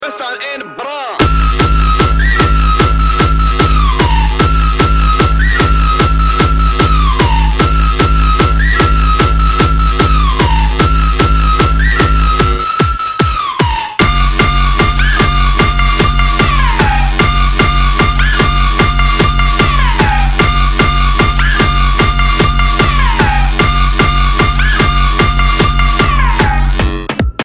громкие
энергичные
быстрые
Hardstyle
Rave